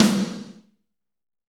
Index of /90_sSampleCDs/Northstar - Drumscapes Roland/DRM_Fast Rock/SNR_F_R Snares x